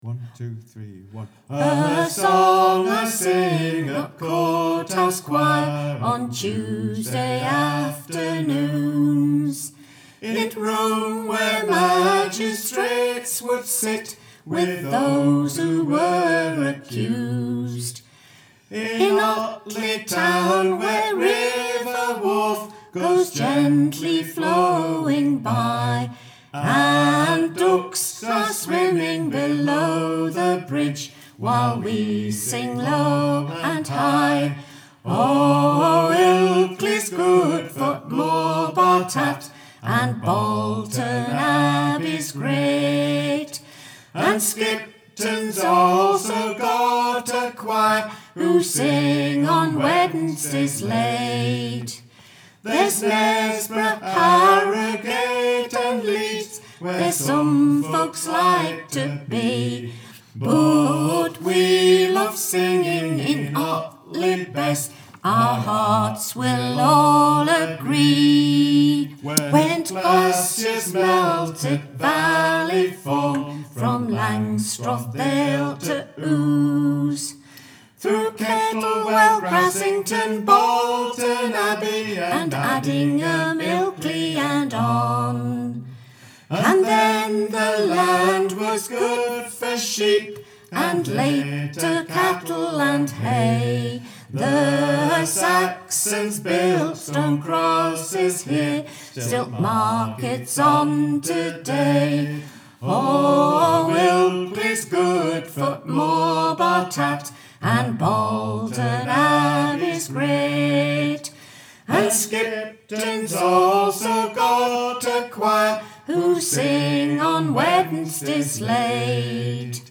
Courthouse-Choir-Song---Tune.mp3